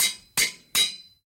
anvil_use.ogg